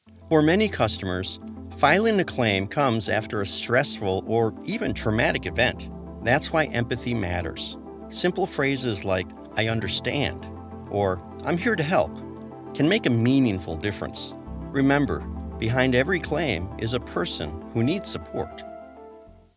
His delivery is warm, steady, and approachable, making even complex material feel manageable.
E- Learning Demos
Elearning explainer corporate professional